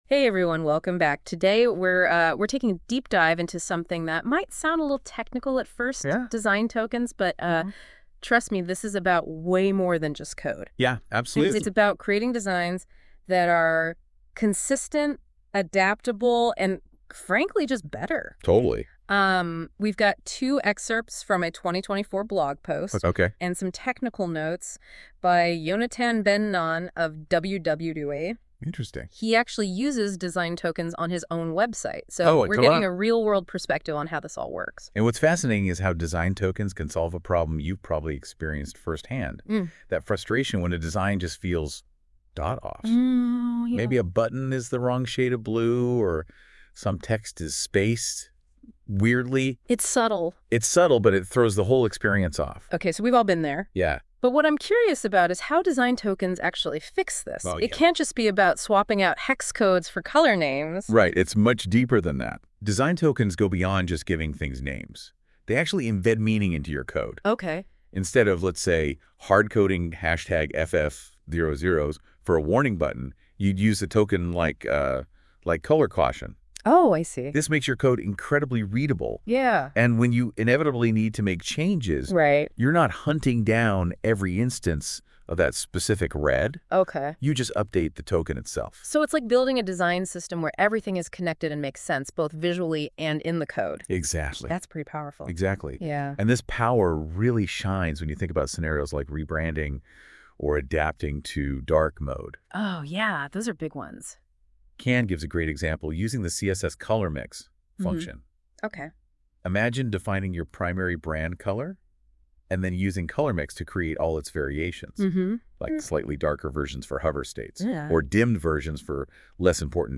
This NotebookLM show covers the article with an energetic, over-the-top, nauseating North American speech.